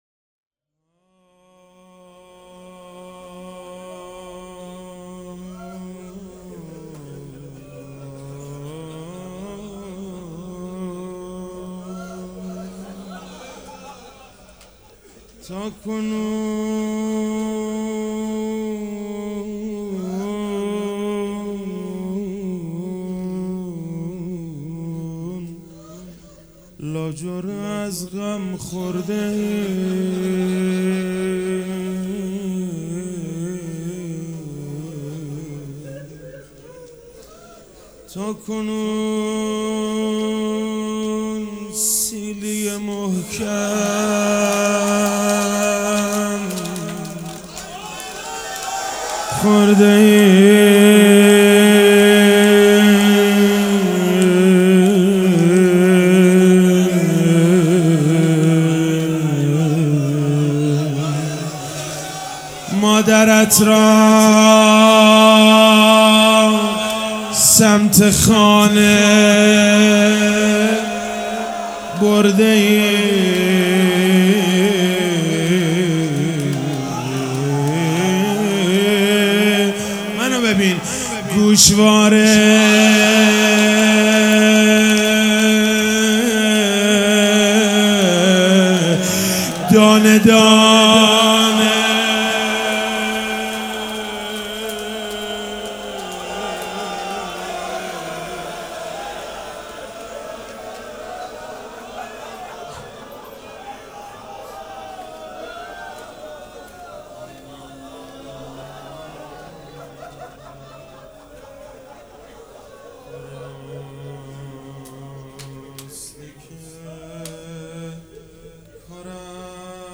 روضه
مداح
مراسم عزاداری شب پنجم